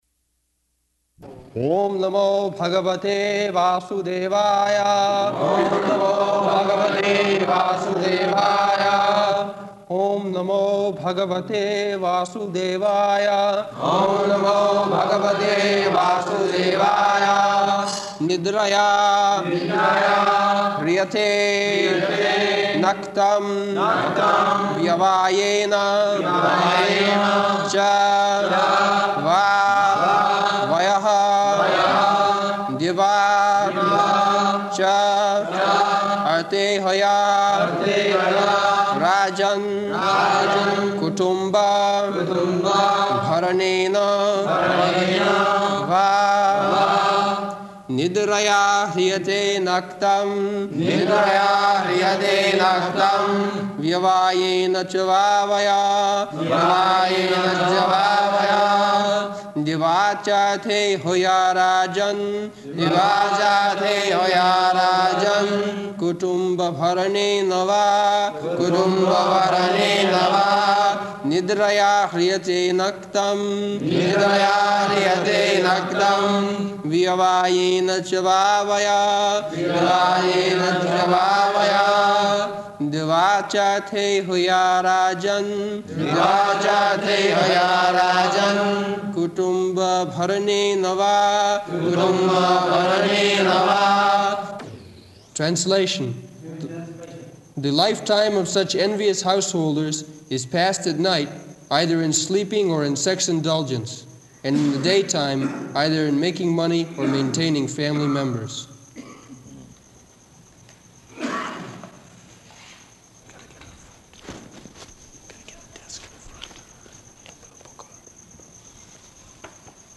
November 6th 1973 Location: Delhi Audio file
[leads chanting of verse, etc.] [Prabhupāda and devotees repeat]